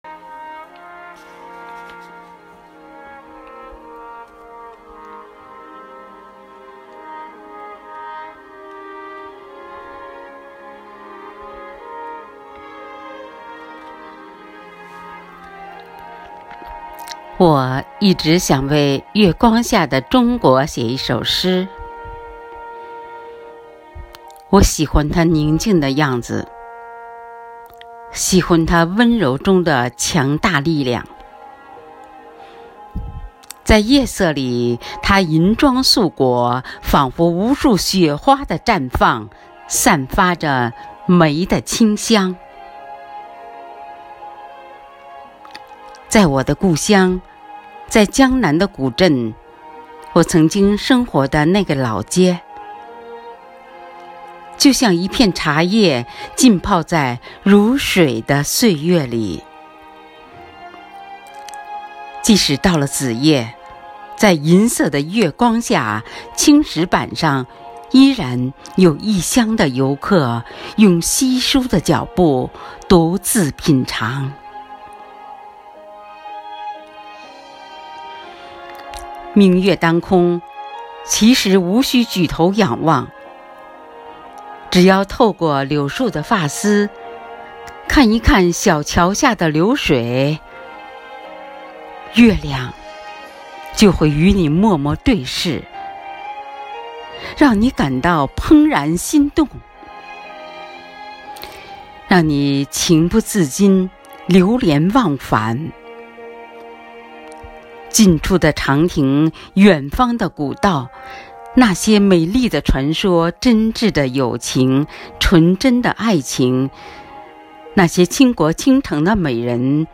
生活好课堂幸福志愿者中国钢研朗读服务（支）队第十一次云朗诵会在五月开启，声声朗诵、篇篇诗稿赞颂红五月，讴歌美好生活，吟诵美丽中国。
《月光下的中国》朗诵